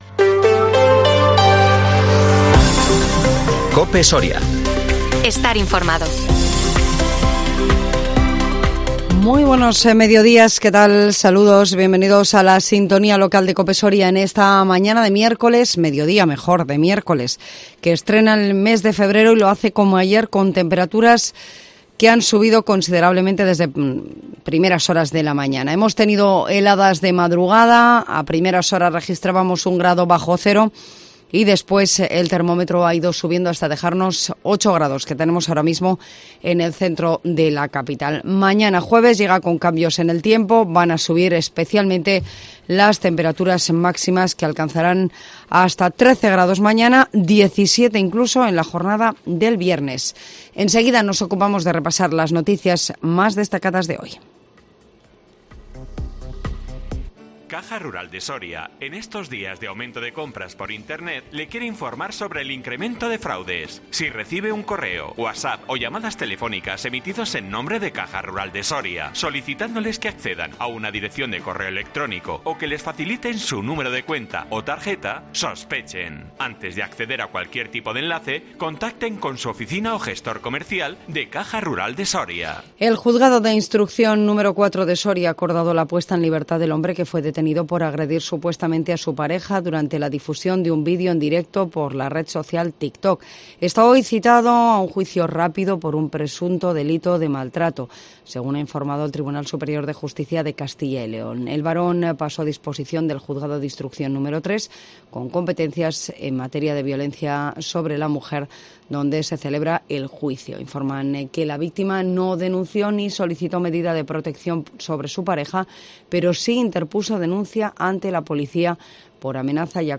INFORMATIVO MEDIODÍA COPE SORIA 1 FEBRERO 2023